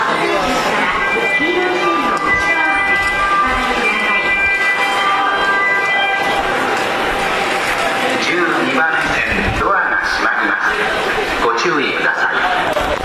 ただ、京成線・新幹線・宇都宮線・高崎線の走行音で発車メロディの収録は困難です。